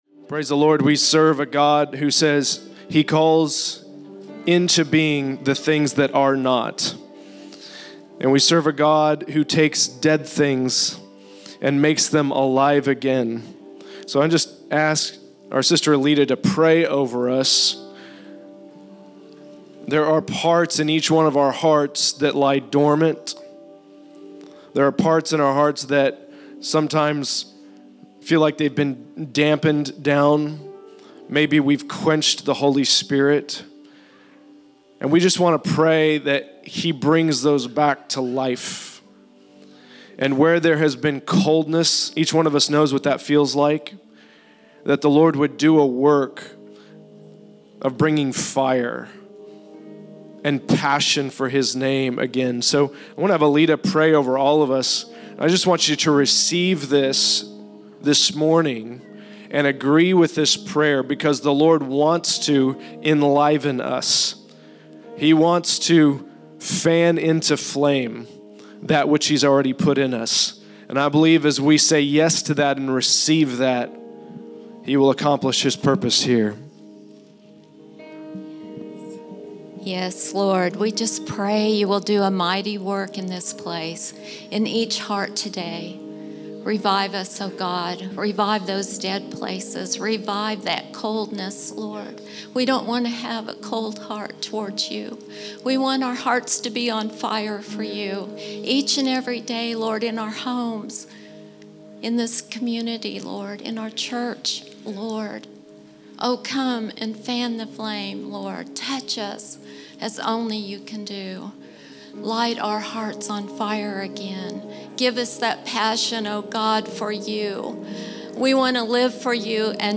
A Prayer for Fire - Following Worship